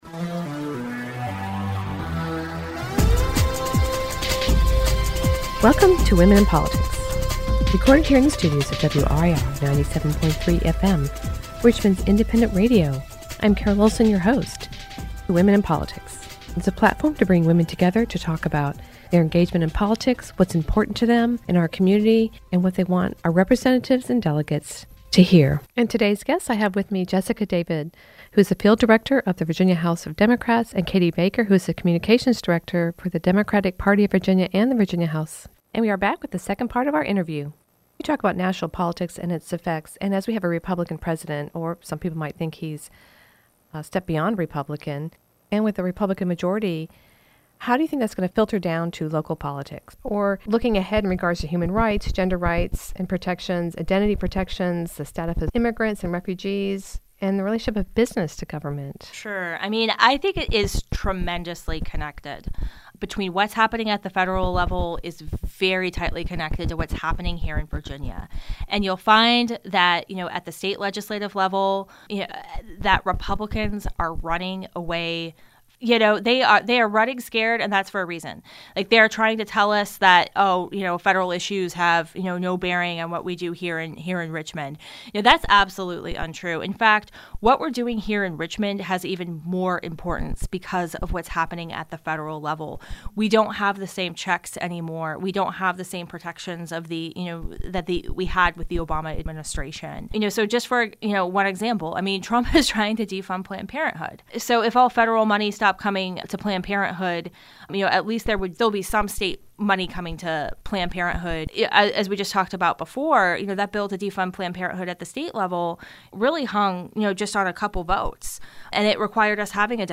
Today we finish our interview with Democratic party Staff talking about what's needed for the future of politics and representing the community in Virginia.